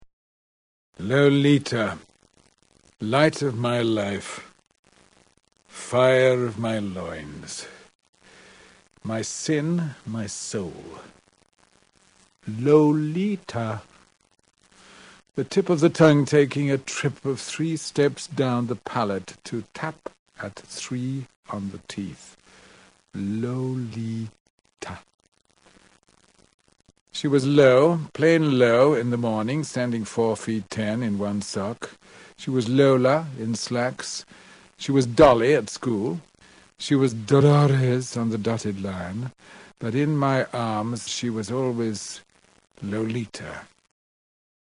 L'attore protagonista del film, James Mason, legge il primo paragrafo di Lolita.
In questa rara registrazione, James Mason, l'interprete principale del film di Kubrick nel ruolo di Humbert Humbert, il professore di letteratura che si innamora della giovane Lolita, legge l'incipit del romanzo di Vladimir Nabokov.